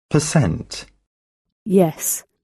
Contemporary DRESS is [ɛ]: